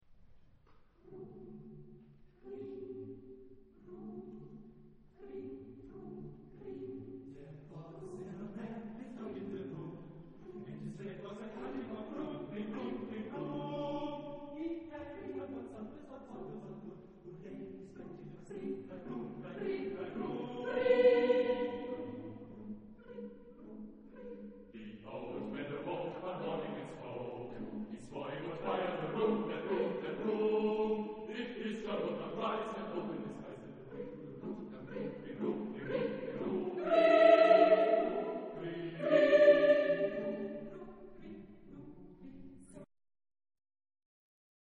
Genre-Style-Forme : Profane ; Cycle ; Ballade
Type de choeur : SATB  (4 voix mixtes )
Tonalité : ré majeur